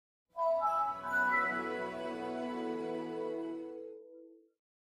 未来的な印象を与える。